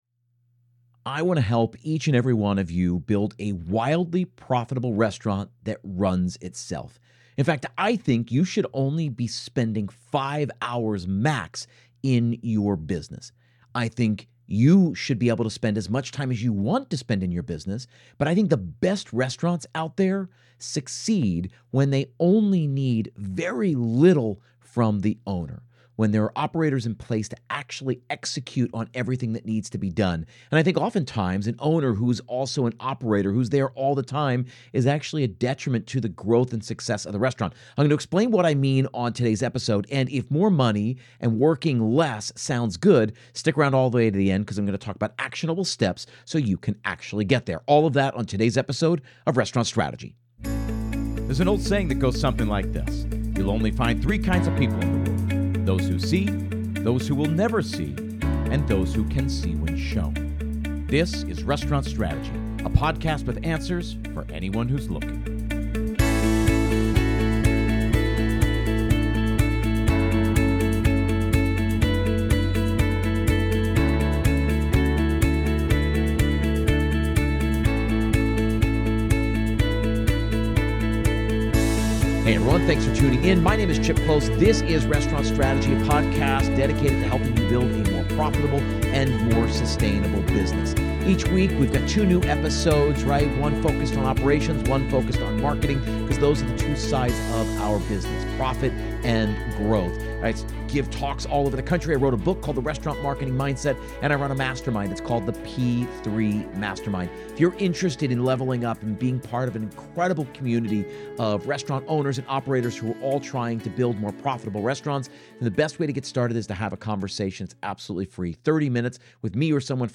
The Restaurant Strategy podcast is dedicated to helping chefs, owners, and operators build more profitable restaurants. Mondays feature an industry interview